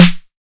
Sn (Reagga).wav